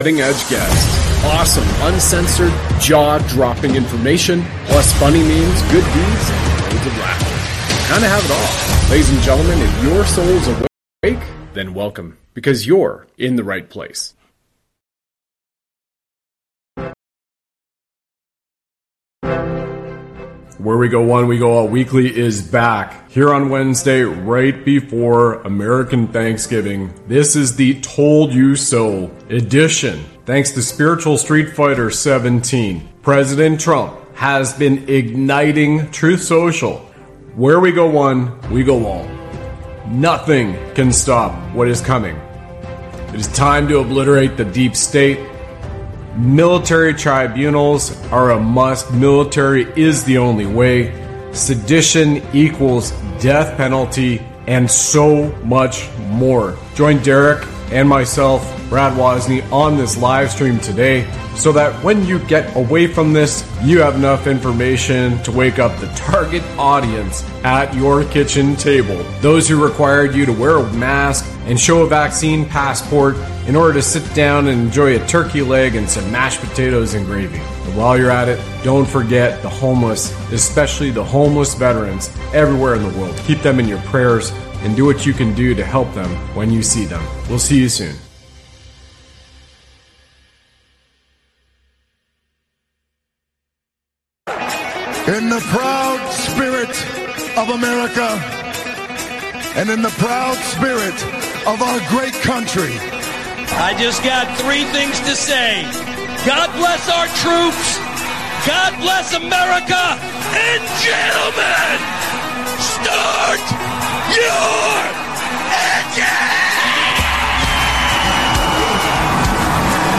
The conversation also includes personal anecdotes and interactions with their audience. ➡ The text discusses the importance of respecting law enforcement and the military, who are just doing their jobs.